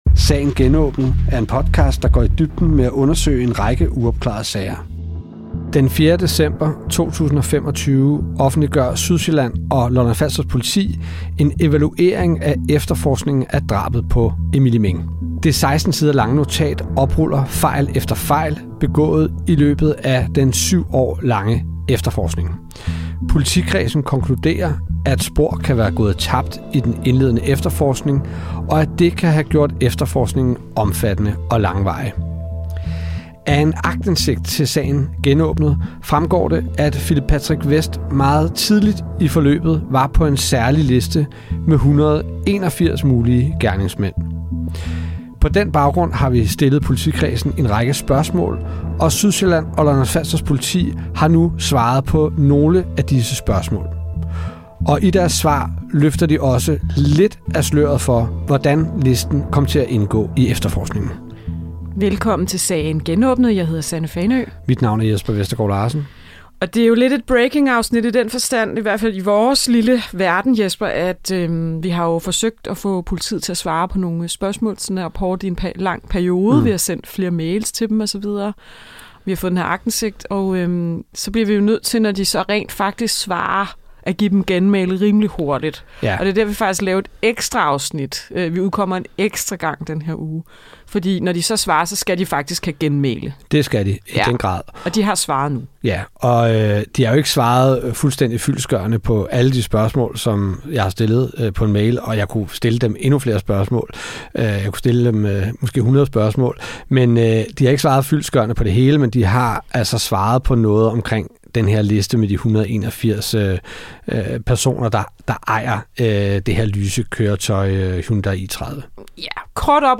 Det har de svaret på nu, men svarer de også på, hvad de derefter gjorde med hans navn på listen? Vi læser hele politiets svar op i dette afsnit.